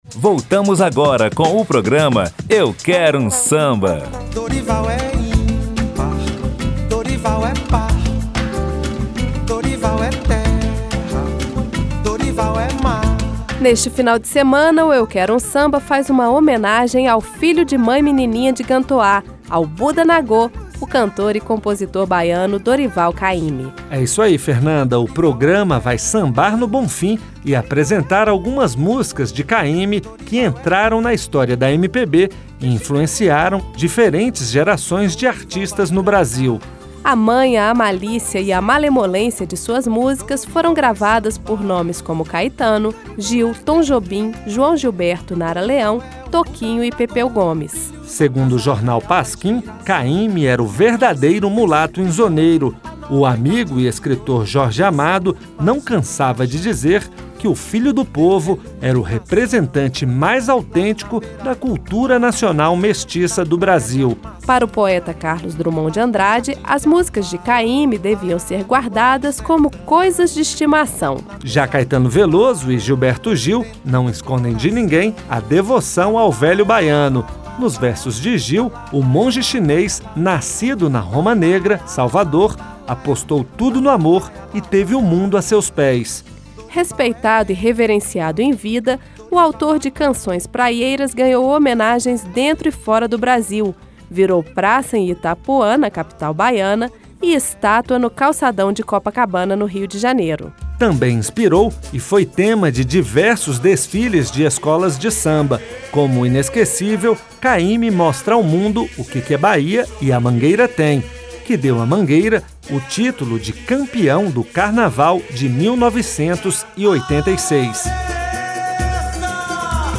Também vai mostrar alguns de seus sambas inesquecíveis e apresentar as músicas escolhidas por convidados do programa.